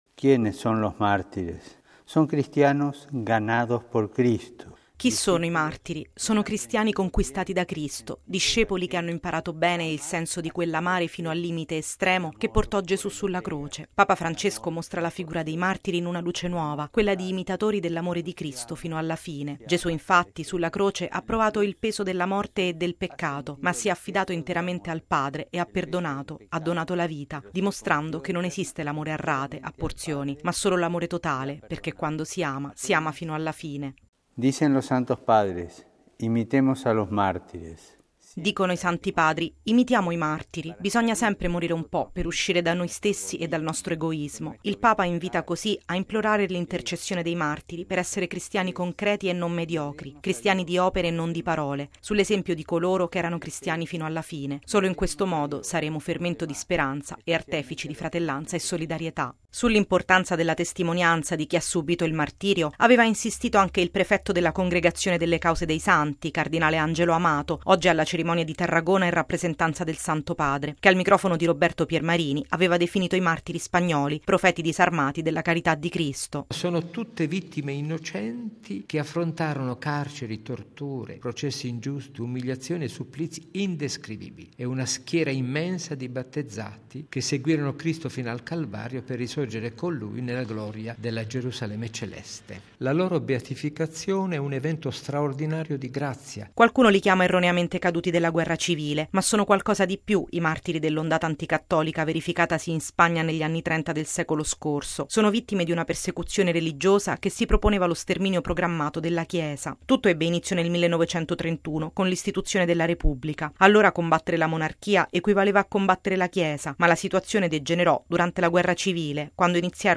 Ai partecipanti, il Papa ha inviato anche un videomessaggio, nel quale ha indicato i martiri come esempio da seguire per uscire da se stessi e aprirsi a Dio. Il servizio